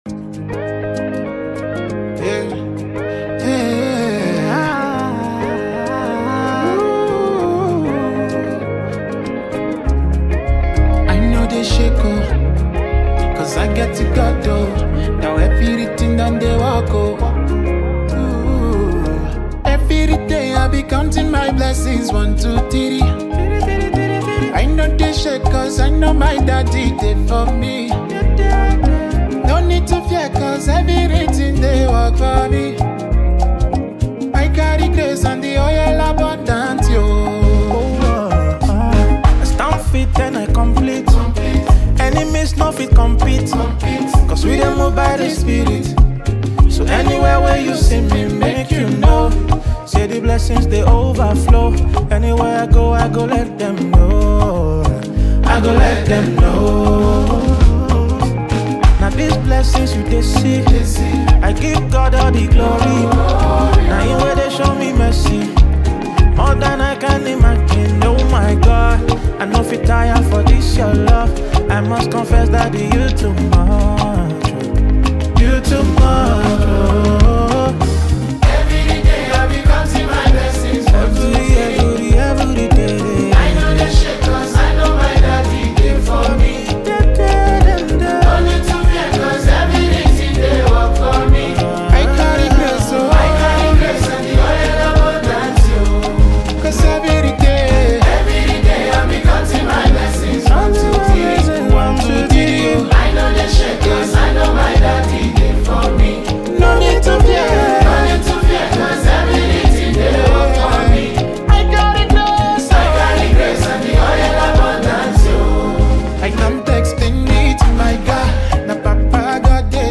Gospel
soulful new single